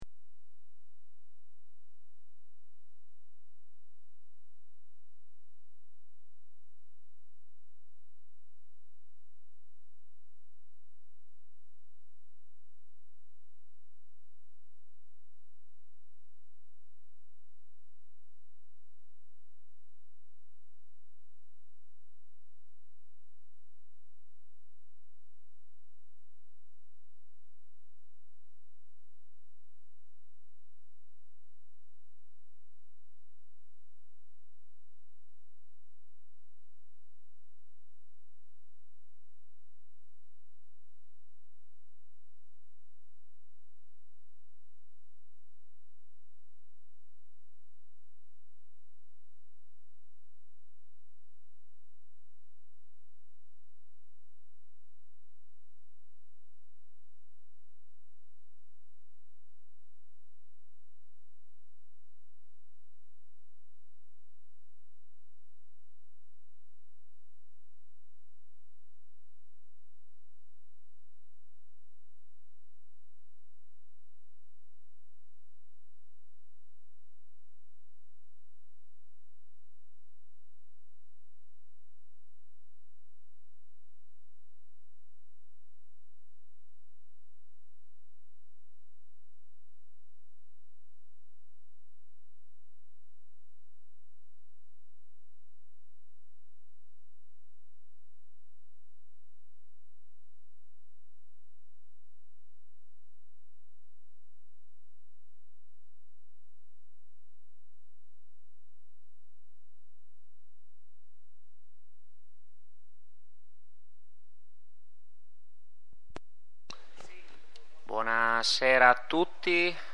Registrazione del Consiglio comunale del 03.03.2026